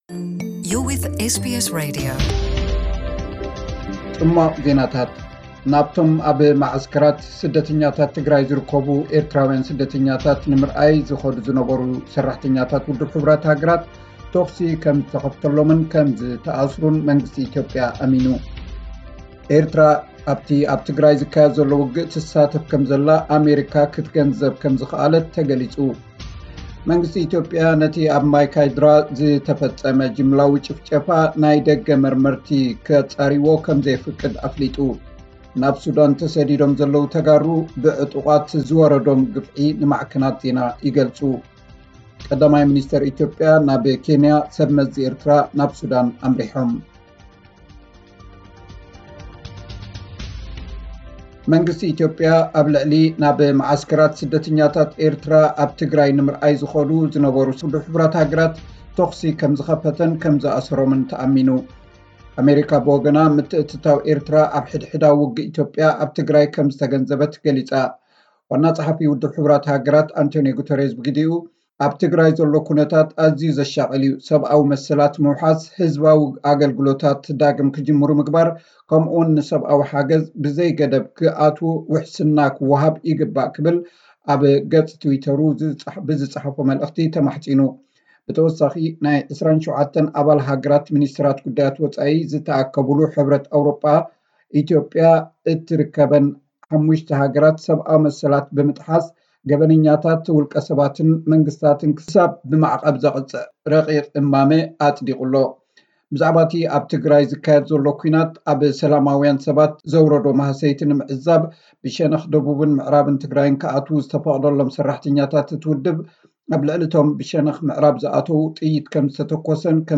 ጽሟቕ ዜናታት፥ ናብቶም ኣብ መዓስከራት ስደተኛታትት ትግራይ ዝርከቡ ኤርትራዊያን ስደተኛታት ንምርኣይ ዝኸዱ ዝነበሩ ሰራሕተኛታት ውሕሃ ተኹሲ ከም ዝኸፈተሎምን ከም ዝኣሰሮምን መንግስቲ ኢትዮጵያ ኣሚኑ።